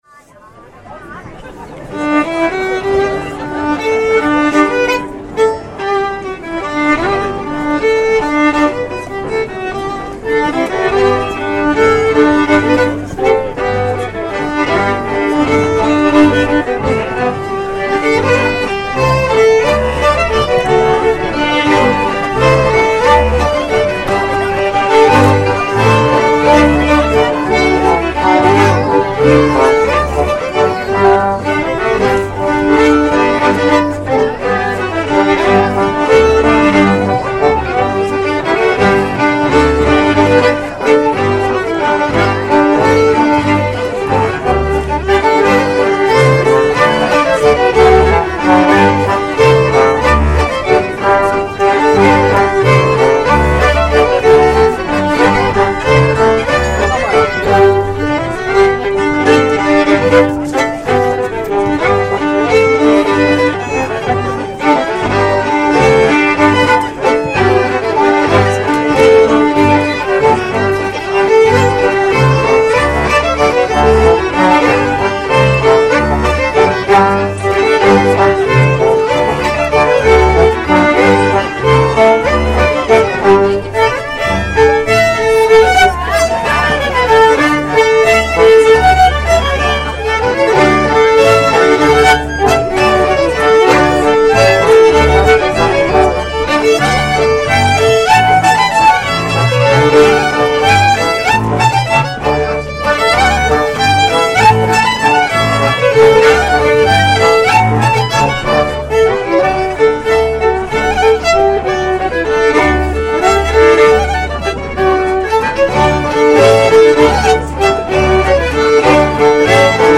Programm für die Auftritte am Drumherum Regen 2012:
Flöten- und Posaunenstimme